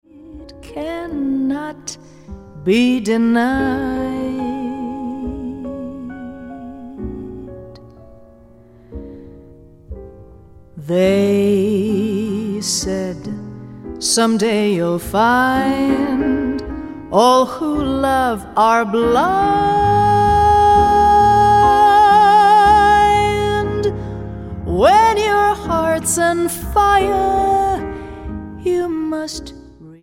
Genre: Vocal